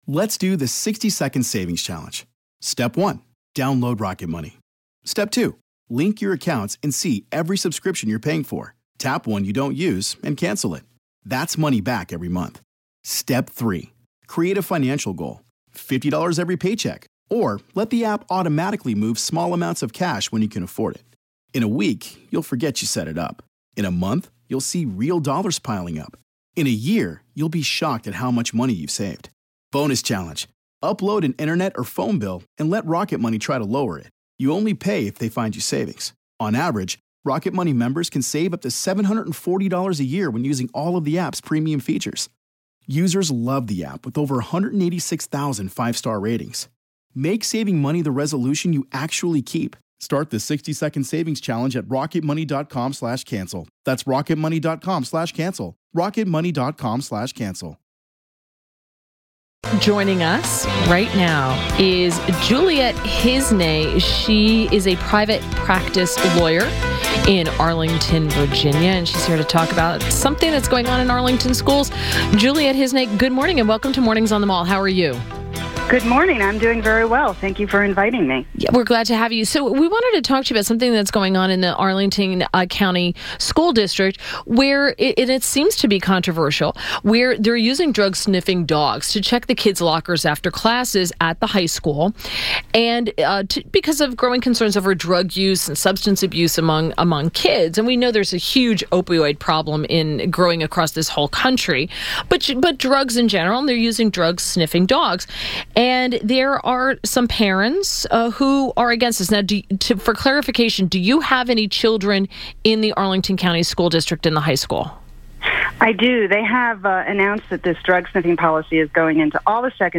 WMAL Interview